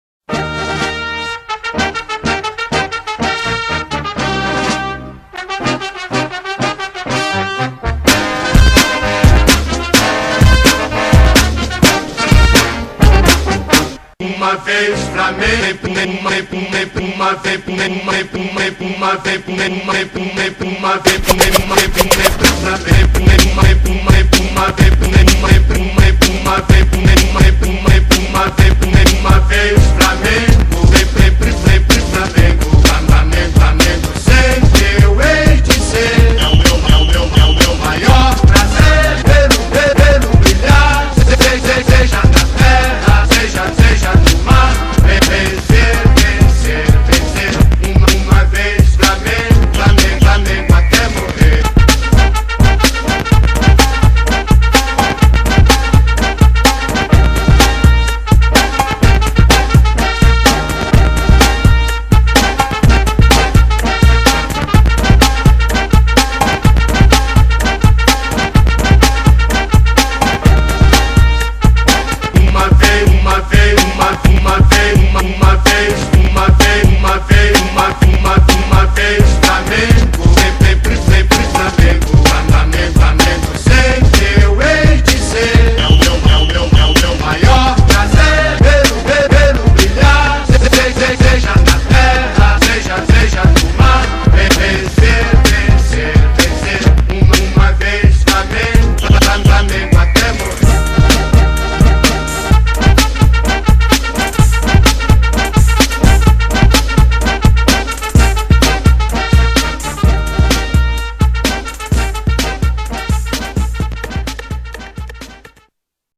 2024-02-20 23:26:27 Gênero: Funk Views